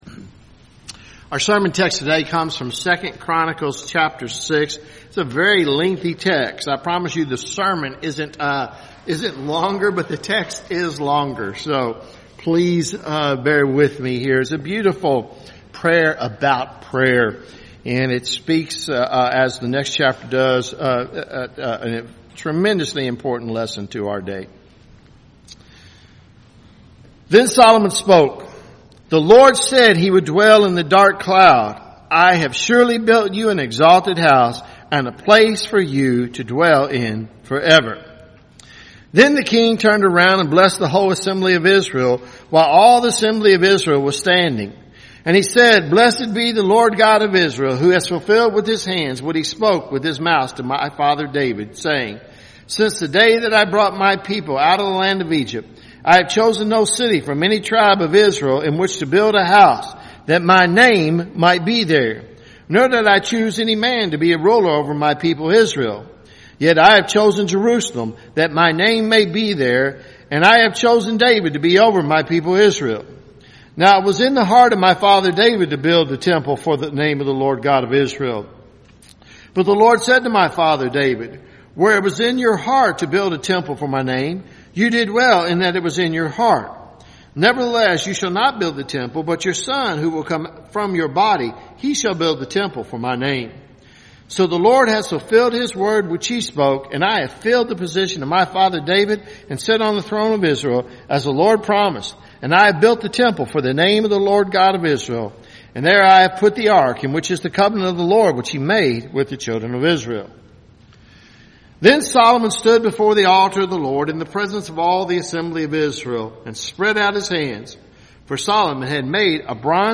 Sermons
6-November-2022-Sermon.mp3